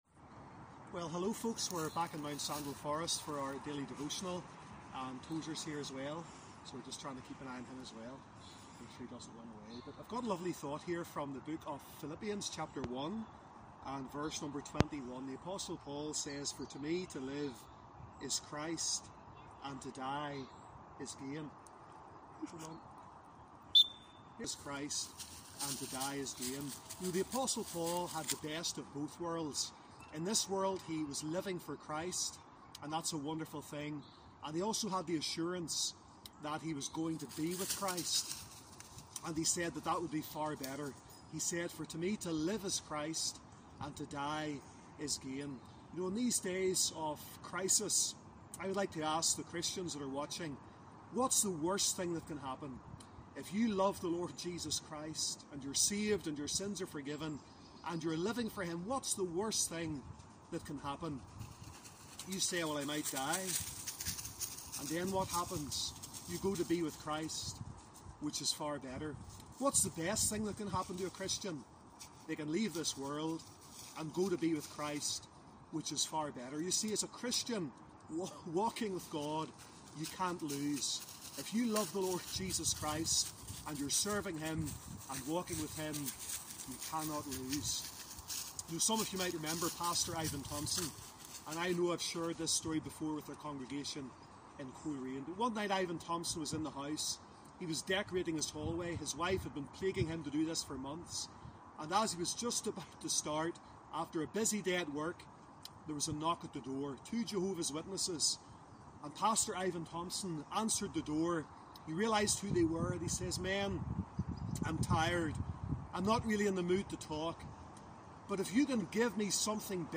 Sermon or written equivalent